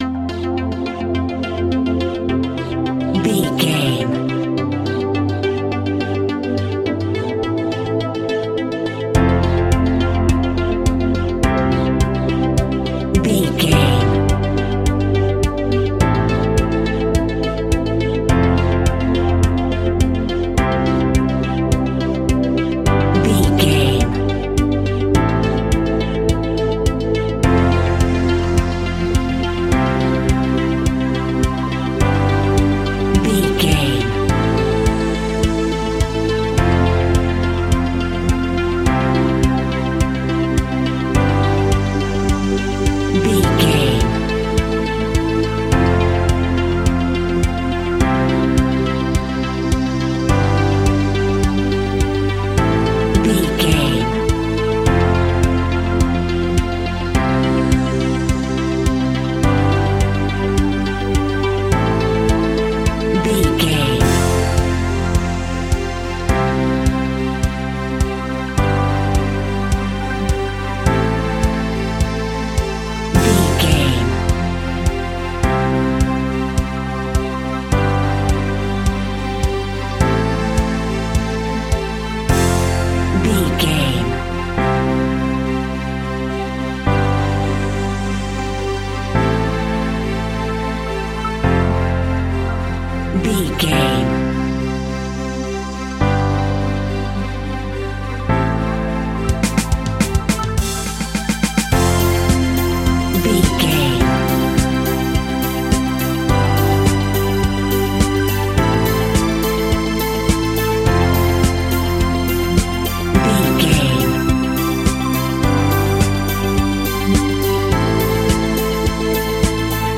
Uplifting
Ionian/Major
energetic
cheesy
instrumentals
indie pop rock music
guitars
bass
drums
piano
organ